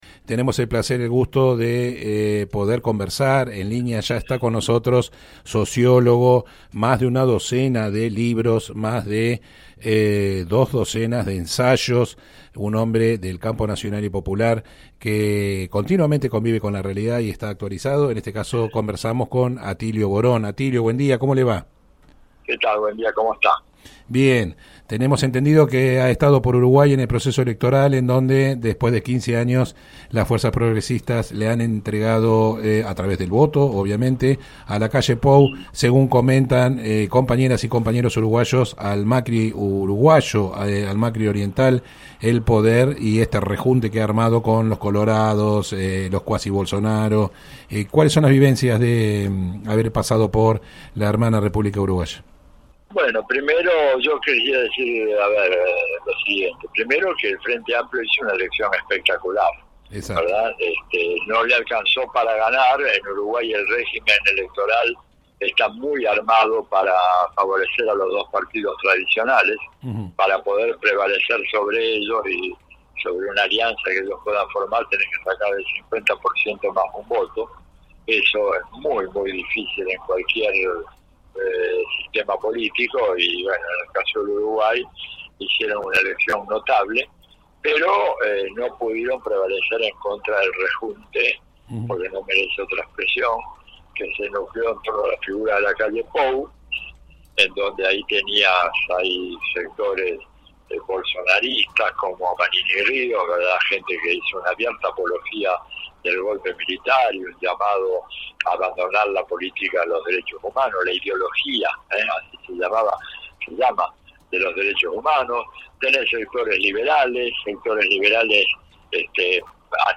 El sociólogo y politólogo, Atilio Boron, opinó en el programa radial Bien Despiertos, producido de lunes a viernes de 7:00 a 9:00 por De la Azotea 88.7, sobre las elecciones en Uruguay.